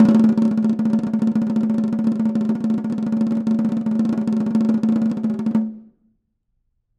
Snare2-rollNS_v5_rr1_Sum.wav